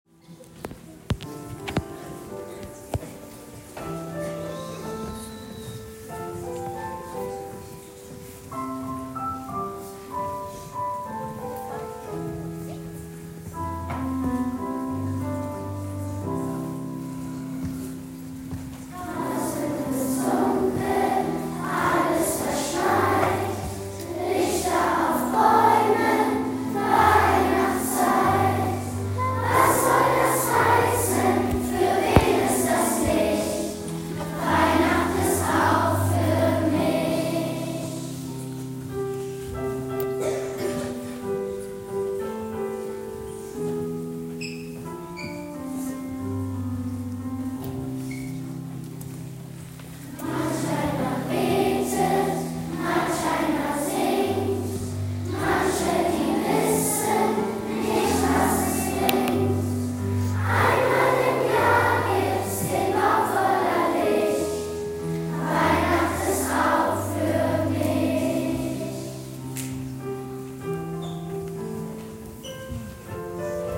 Weihnachtskonzert
In der weihnachtlich beleuchteten Turnhalle haben die Kinder der Klassen 3 & 4 ein kleines, herzerwärmendes Weihnachtskonzert gesungen.
Die Stimmen der 200 Chorkinder zauberten dem Publikum ein Lächeln und manchen sogar eine Träne der Rührung ins Gesicht.